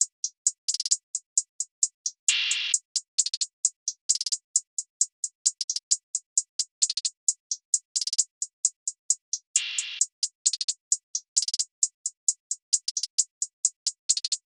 Cardiak_HiHat_Loop_3_132bpm.wav